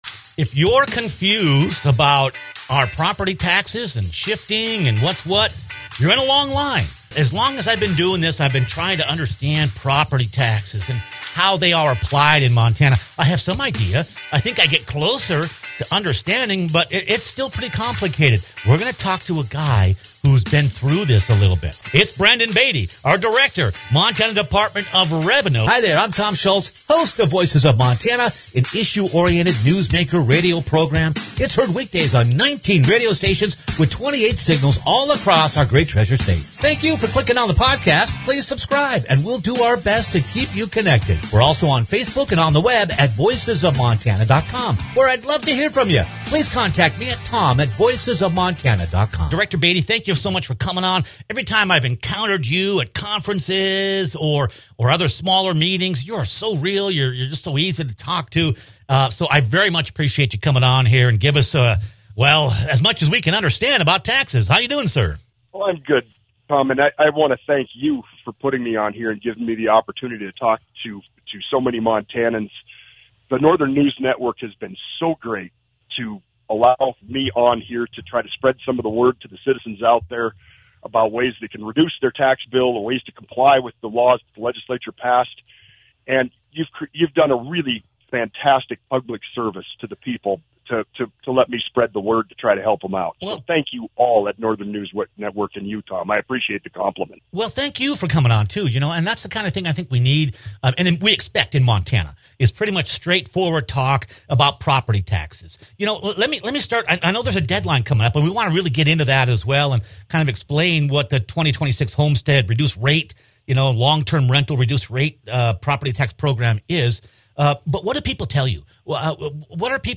Montana Revenue Director Brendan Beatty joins Voices of Montana to walk us through the new homestead and long‑term rental reduced‑rate programs, who qualifies, and how to apply, stressing the March 1 application deadline, which was extended to March 20 shortly after the live airing of this radio show.